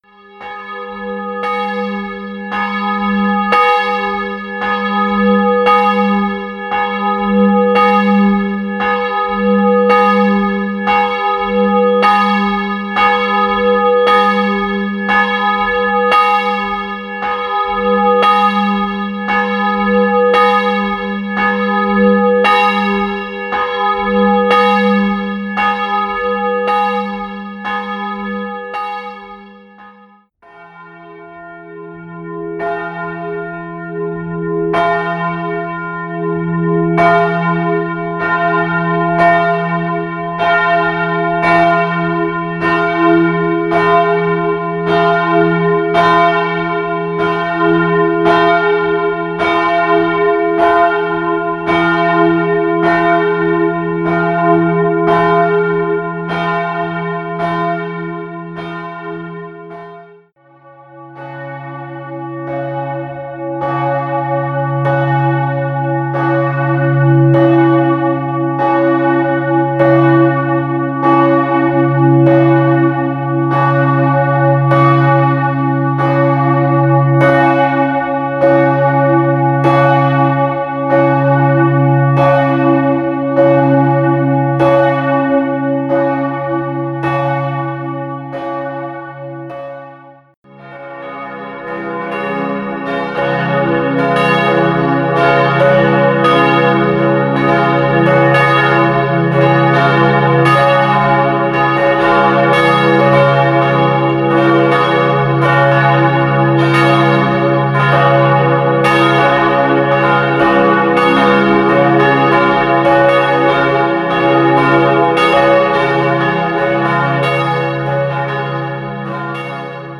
Glockenstuhl
Die kleinste Glocke hat einen Durchmesser von 104,5 cm und ein Gewicht von ca. 678 kg, gefolgt von der nächst größeren Glocke mit einem Gewicht von ca. 1211 kg und einem Durchmesser von 126,2 cm.
Gestimmt auf den Dur-Akkord des’-f-as’ sind diese drei Bronze-Glocken von hoher gusstechnischer Qualität.
Seither werden die Glocken durch Elektromotoren angetrieben.
DREIERGELÄUT ST. WENZEL
Dreiergeläut St. Wenzel.mp3